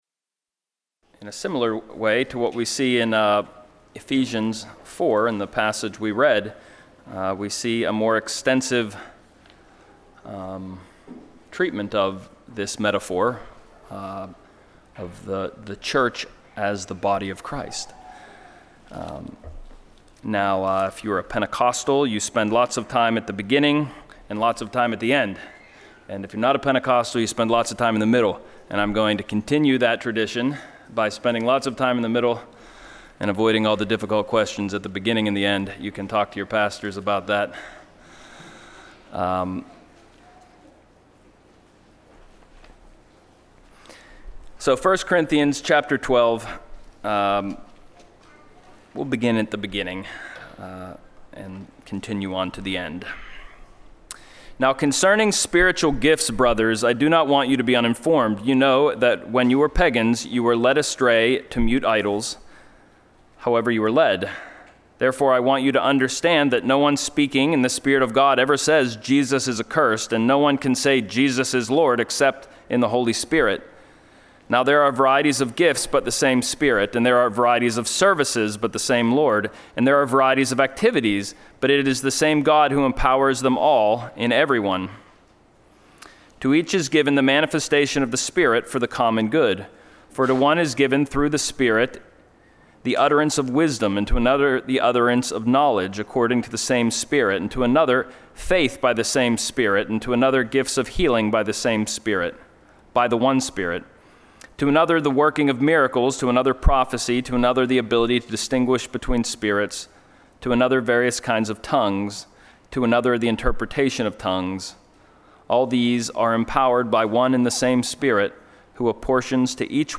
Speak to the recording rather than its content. Joint Service with 1st RPC: “Body Life”